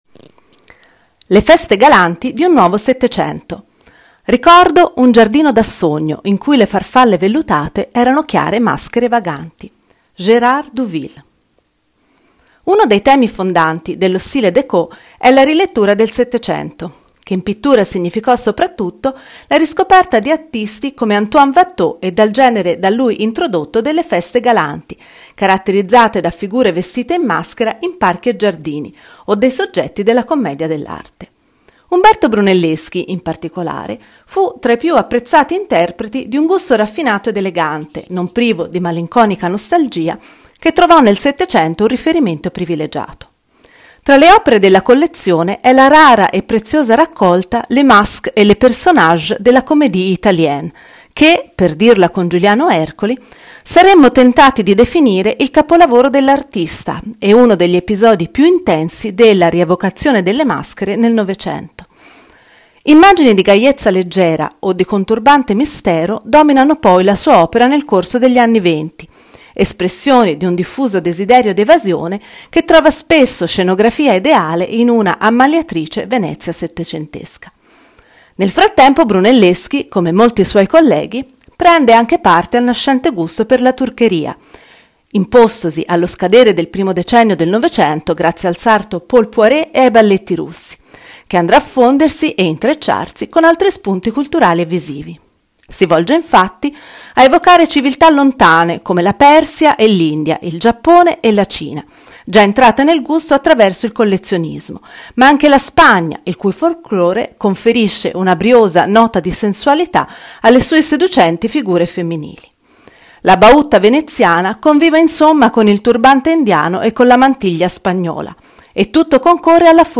AUDIOGUIDA MOSTRA